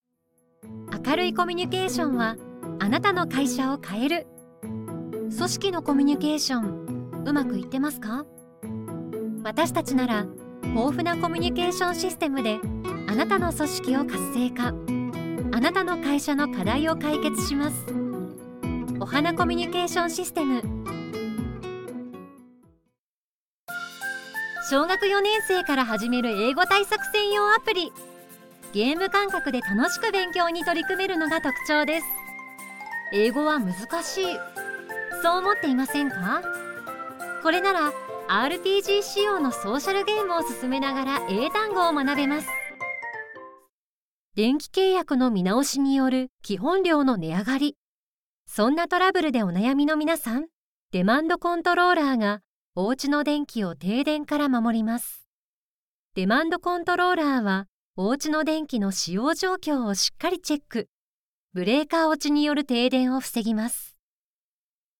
• 【CM】①さわやかな　②明るく　③落ち着いた